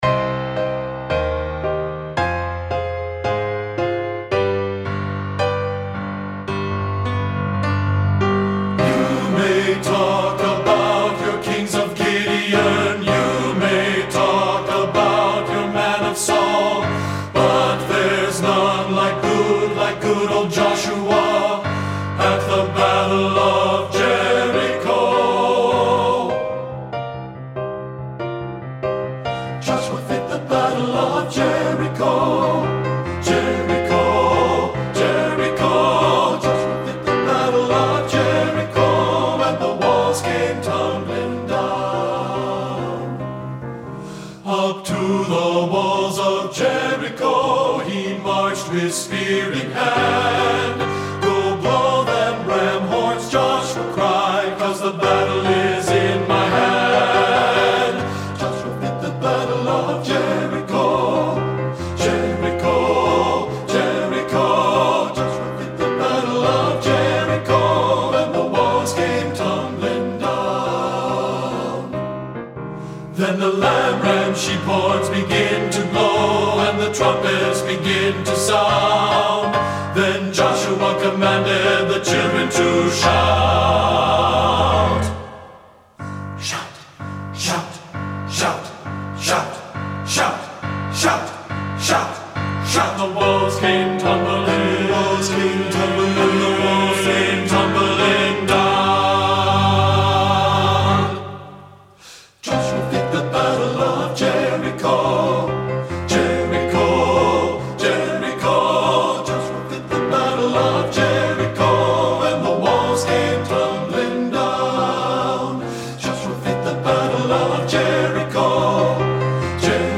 Voicing: TTB